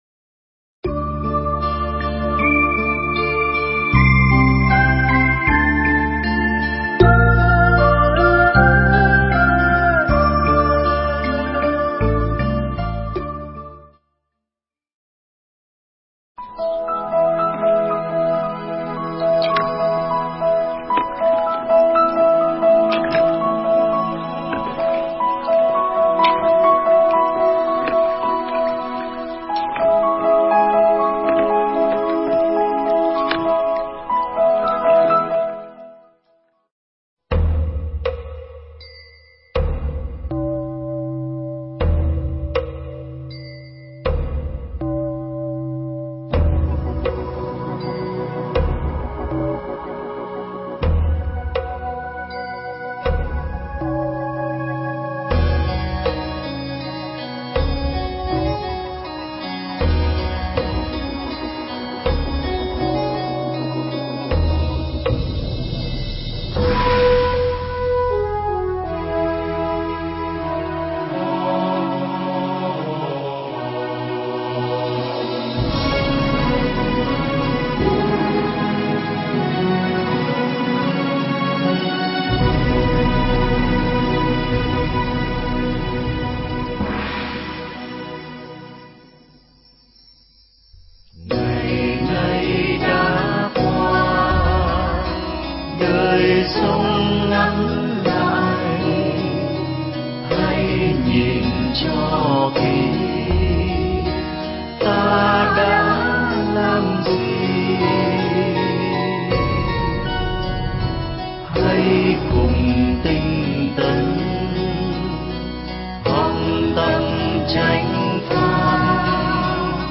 Tải mp3 pháp thoại Ngủ Quên Trong Lửa Phần 1